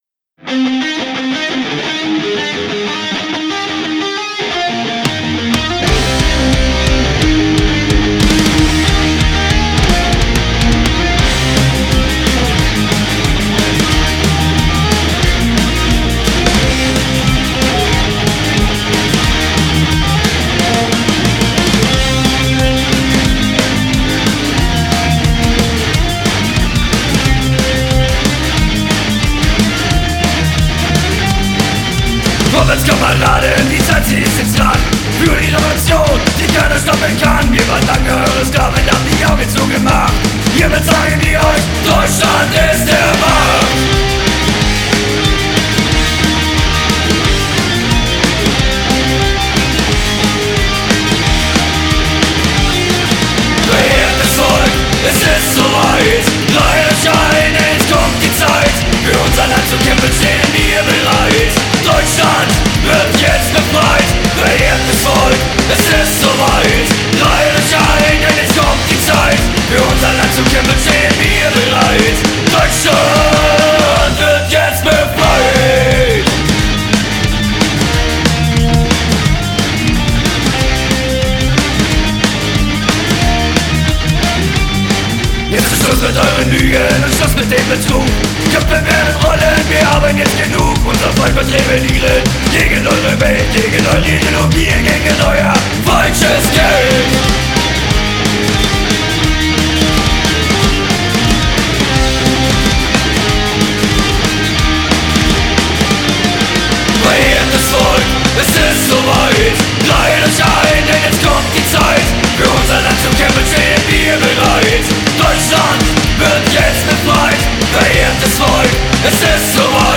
richtig melodischer und zeitgemäßer Politrock.
der fetzige Riff zu Beginn der ersten Nummer